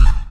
drop.ogg